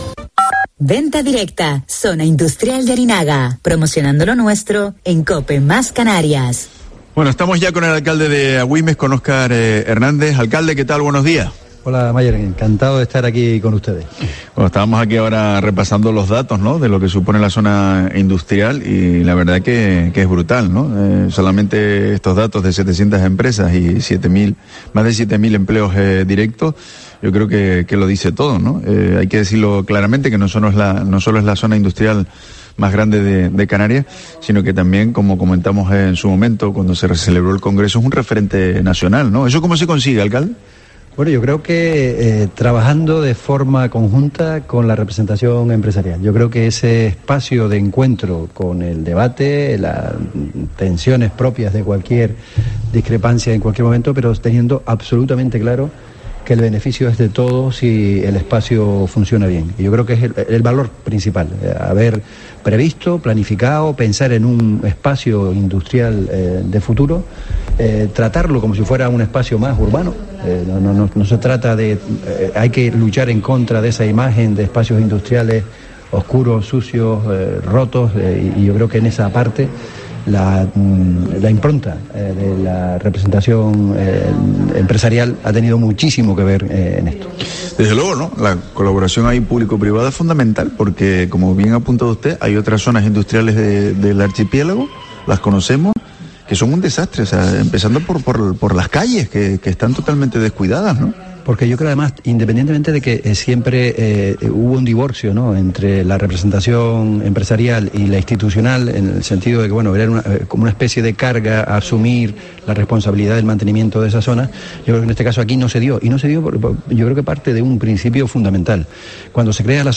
Entrevista a Óscar Hernández, alcalde de Agüimes y vicepresidente primero de la FECAM